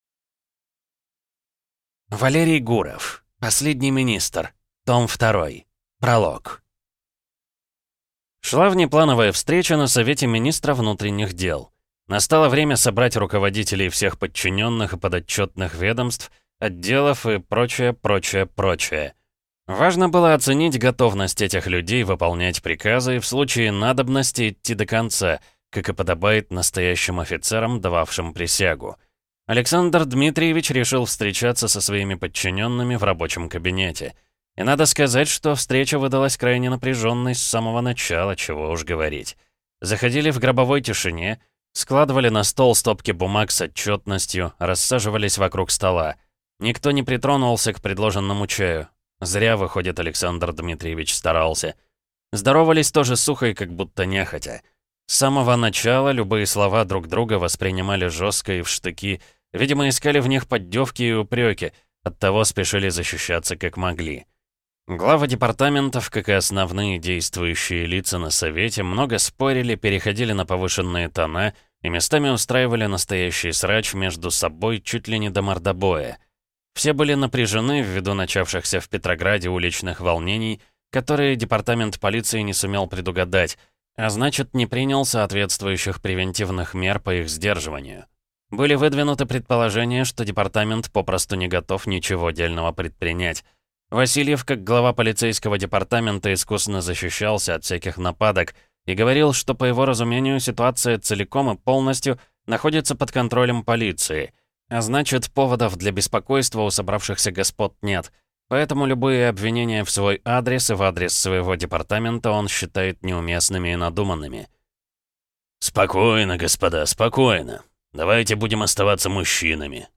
Аудиокнига «Последний министр. Том 2».